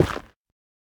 Minecraft Version Minecraft Version snapshot Latest Release | Latest Snapshot snapshot / assets / minecraft / sounds / block / netherrack / break3.ogg Compare With Compare With Latest Release | Latest Snapshot